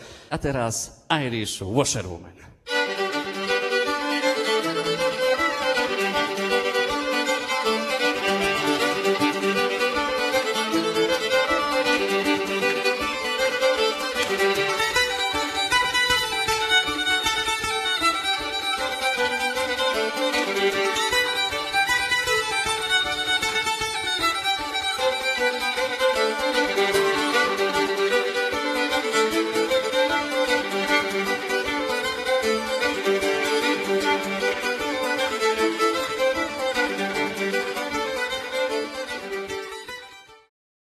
jig